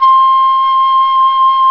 Flute Hi Sound Effect
Download a high-quality flute hi sound effect.
flute-hi.mp3